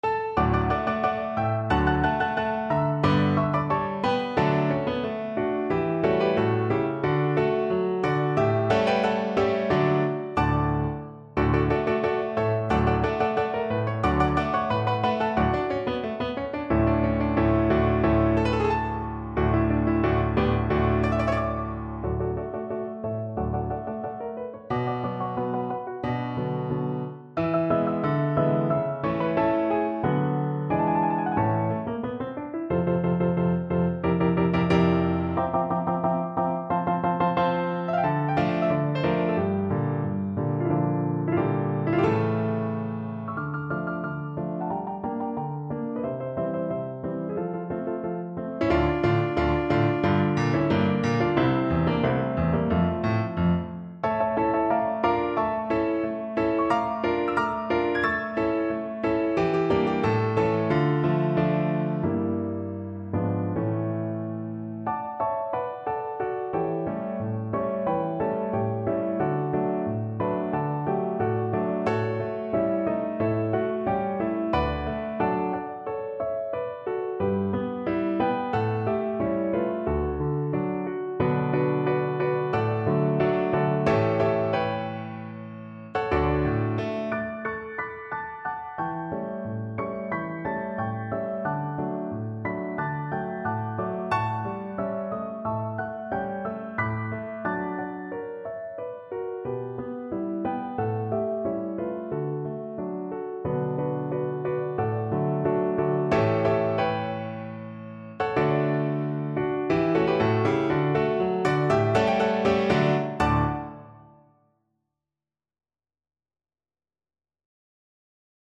2/4 (View more 2/4 Music)
Allegretto =90
Classical (View more Classical Tenor Voice Music)